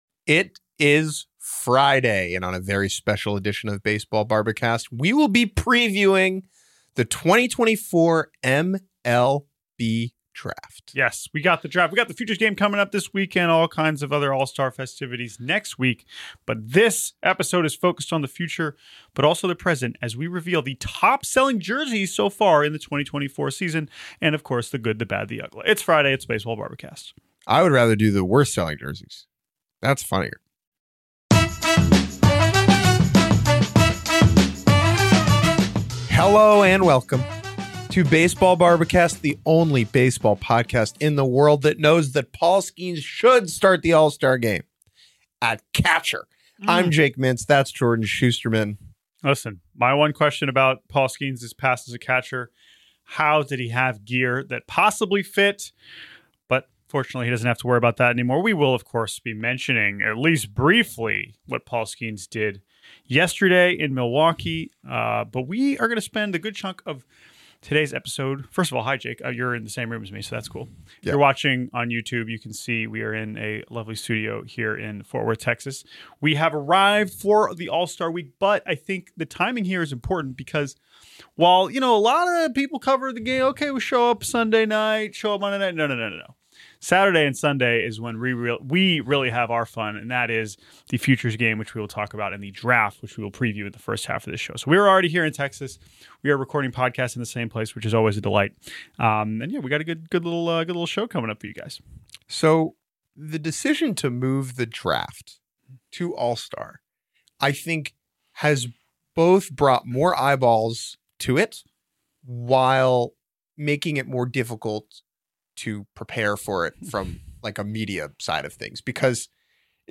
The Baseball Bar-B-Cast has taken the show on the road for the 2024 edition of the Major League Baseball All-Star Game in Arlington, Texas.